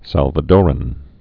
(sălvə-dôrən) or Sal·va·do·ri·an (-dôrē-ən)